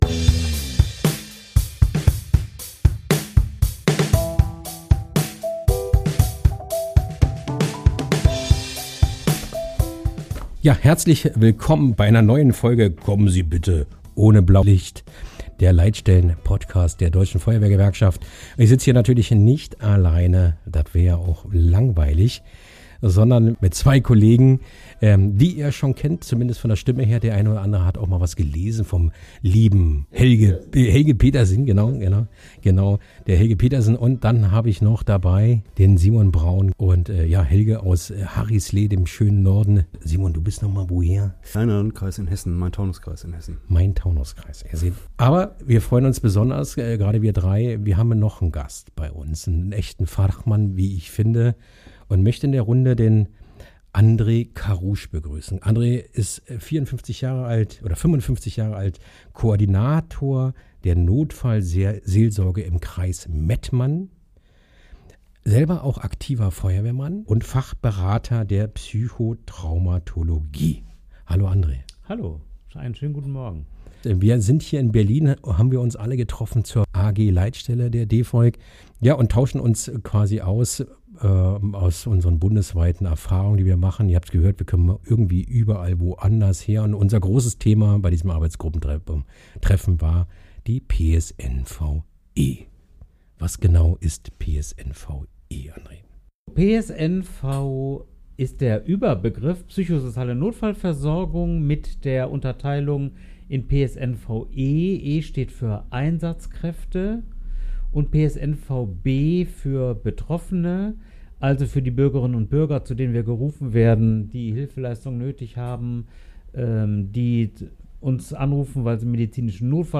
Was ist PSNV genau und warum werden dabei die Leitstellen der Feuerwehr und des Rettungsdienstes vergessen? Über die besonderen Herausforderungen diskutieren wir mit einem echten Fachmann über das Thema.